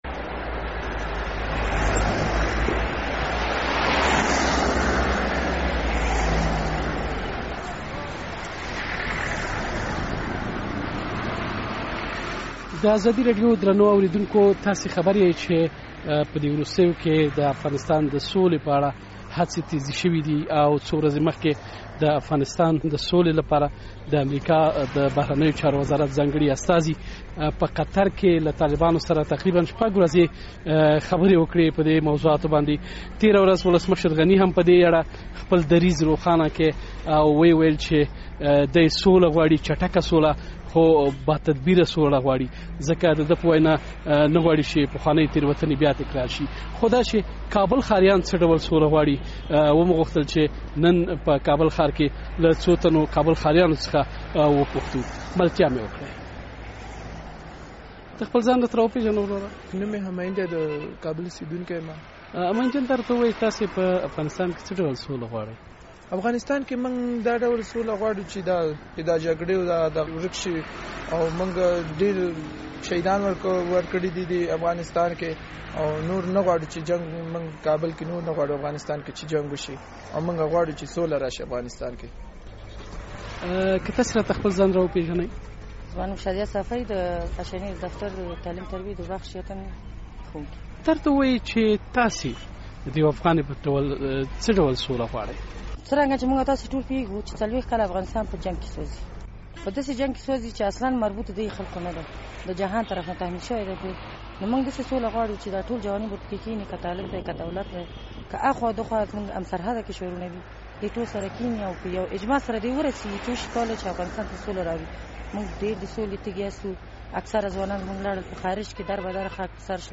د کابل ښار منډوي سیمه
ښاري راپور